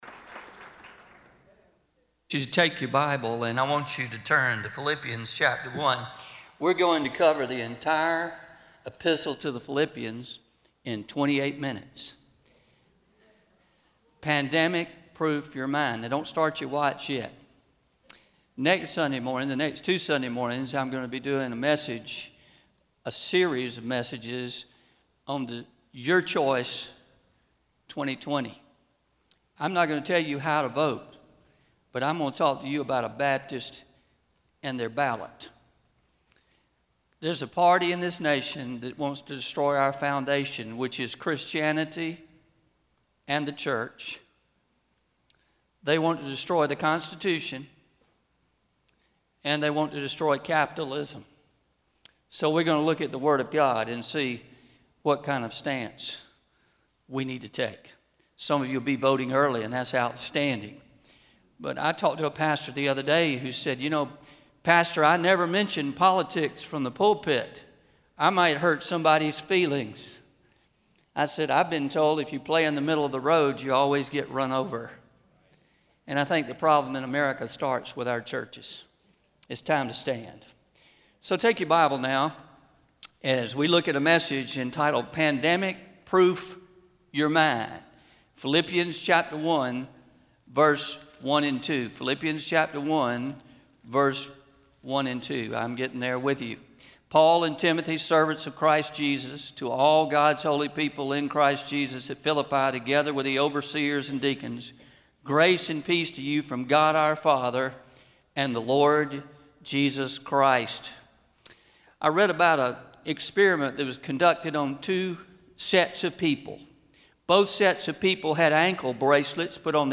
09-16-20am Sermon – Pandemic Proof Your Mind – Traditional – Pleasant Valley South Baptist Church